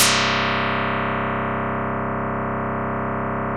Index of /90_sSampleCDs/OMI - Universe of Sounds/EII Factory Library/85 Fretless Bass&Plucked Piano